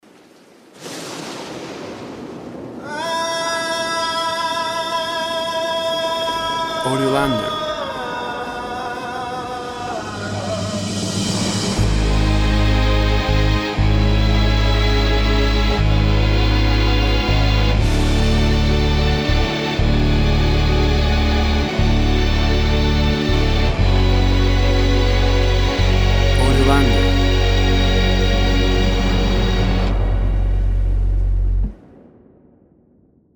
Incidental music for dawn or hope scene.
Tempo (BPM) 80